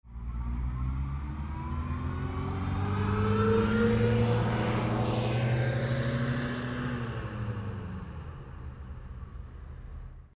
Движение автомобиля задним ходом